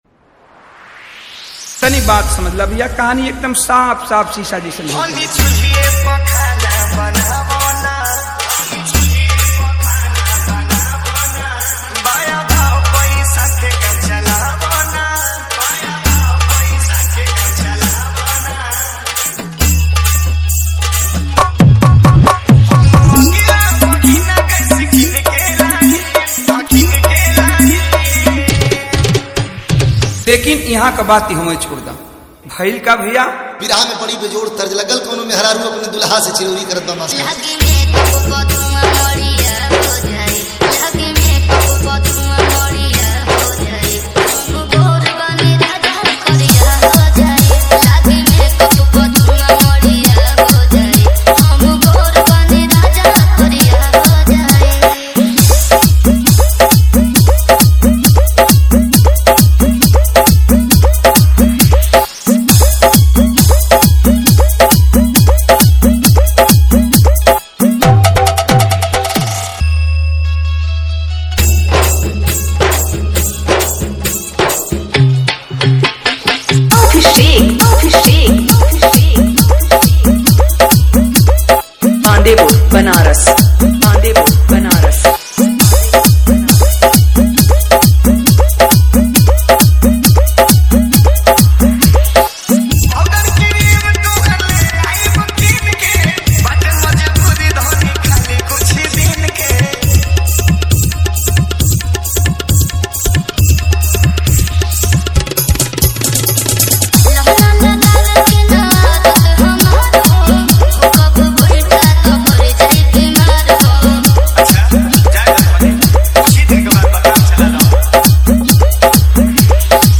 Bhojpuri Love DJ Remix